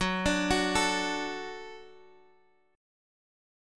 F#m#5 chord